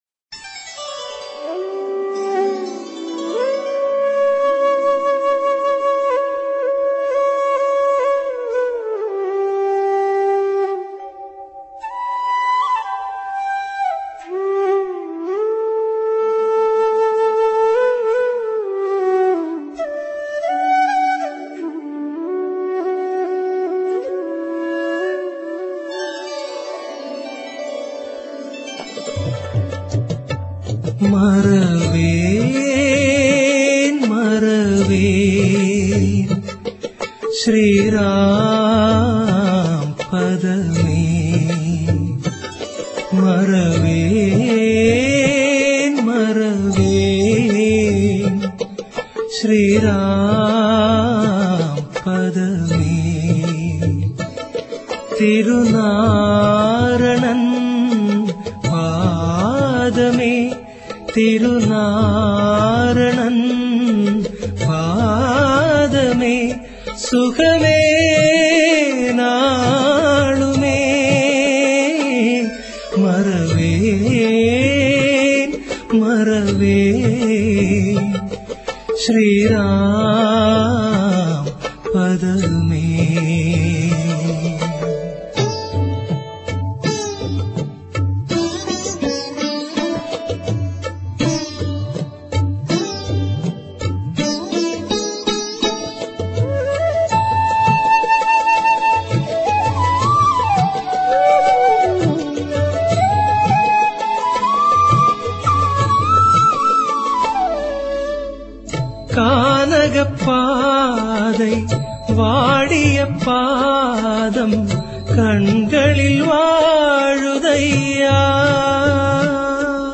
devotional album songs